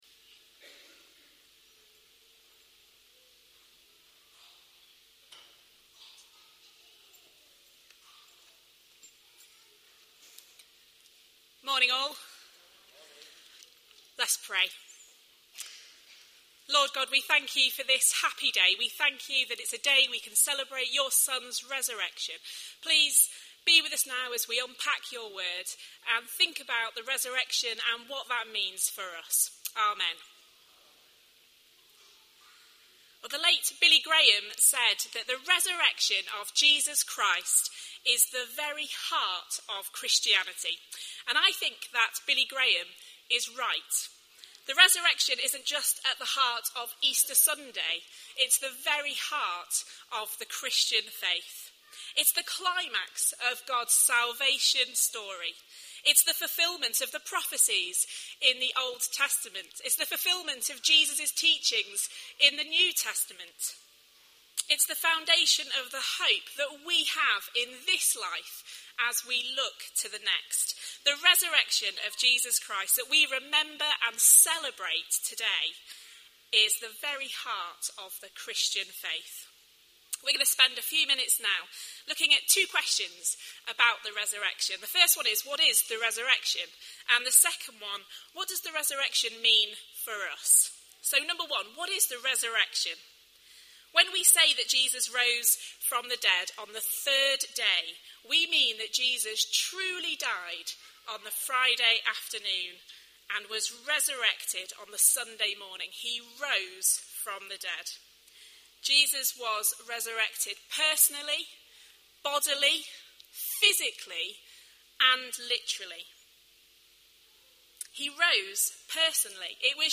These sermons are those which are not part of any series and are taken from the Sunday services at St George’s.